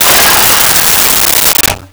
Metal Crash 02
Metal Crash 02.wav